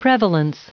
Prononciation du mot prevalence en anglais (fichier audio)
Prononciation du mot : prevalence
prevalence.wav